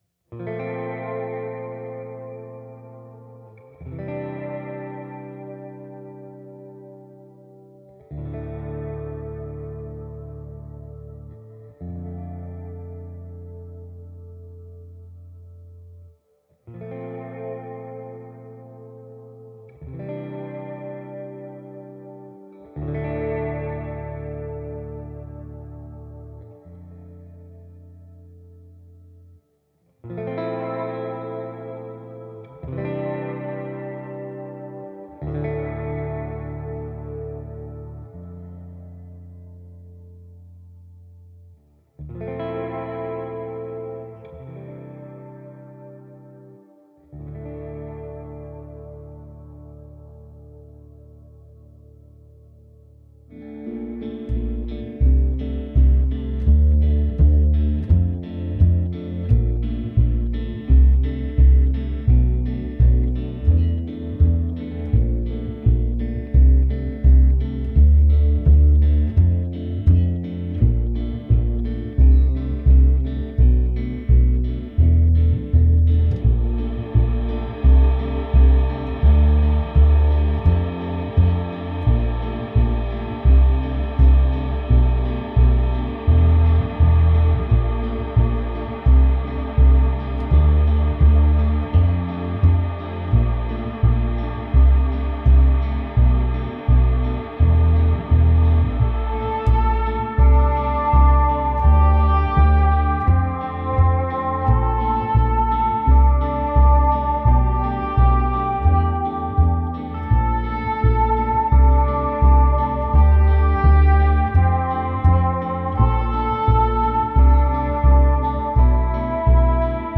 I like to think of this music as futuristic, dark Americana.
Tagged as: Alt Rock, Experimental, Prog Rock